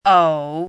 “呕”读音
ǒu
国际音标：ou˨˩˦;/ou˥;/ou˥˧
ǒu.mp3